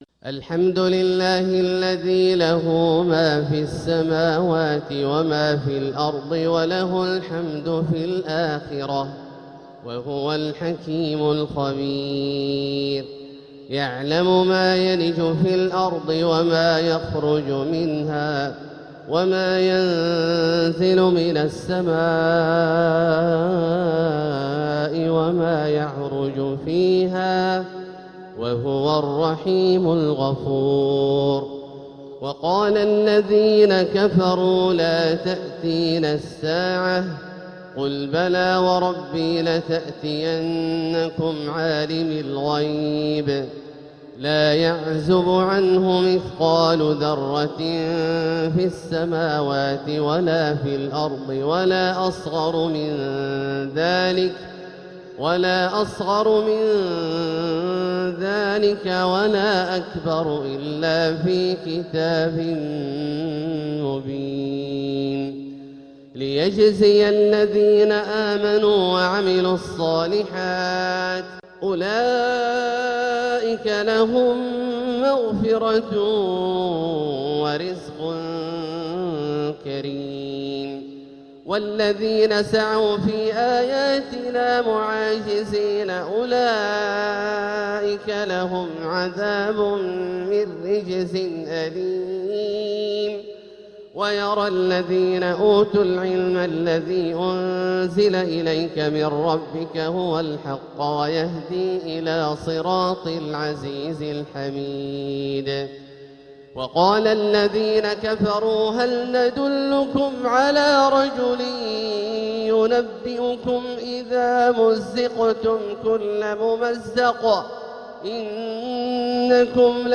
تلاوة خيالية بالغة الروعة لـ سورة سبأ كاملة للشيخ د. عبدالله الجهني من المسجد الحرام | Surat Saba > تصوير مرئي للسور الكاملة من المسجد الحرام 🕋 > المزيد - تلاوات عبدالله الجهني